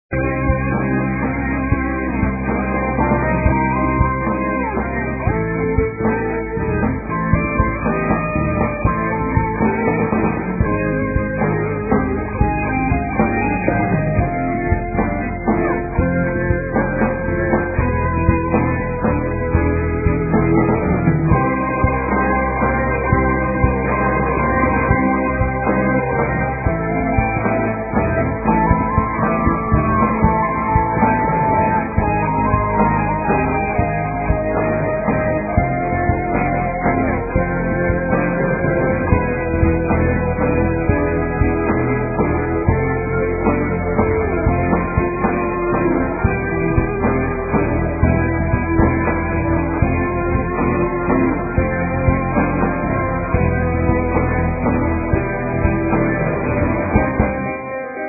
Uma sonoridade extremamente original.